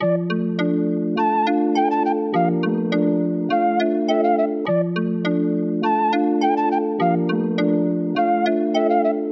舞厅拔萃
描述：主要的激光器类型的拨动
标签： 103 bpm Dancehall Loops Bells Loops 1.57 MB wav Key : Unknown
声道立体声